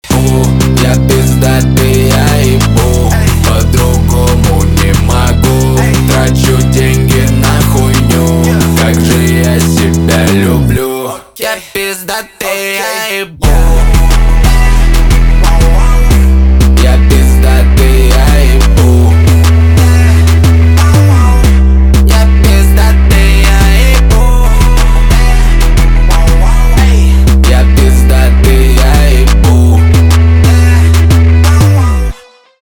русский рэп
басы , гитара
жесткие